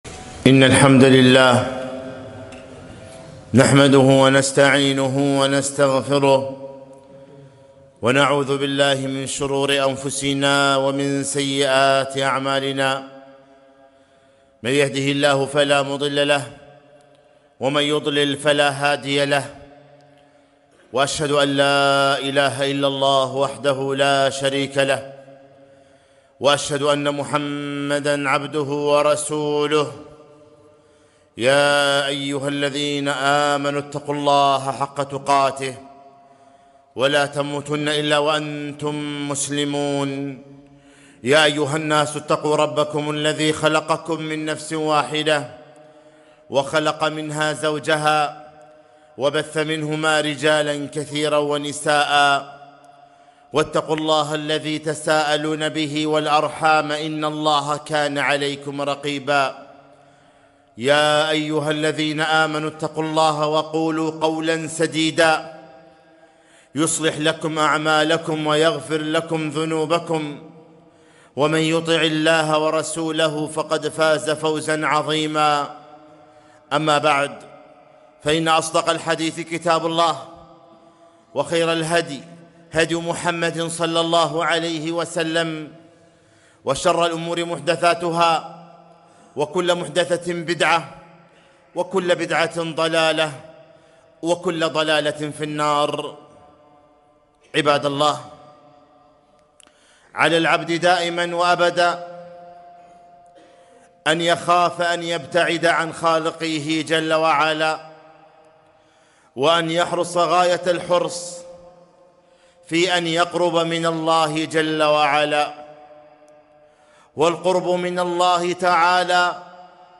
خطبة - الأسباب المعينة على ترك الذنوب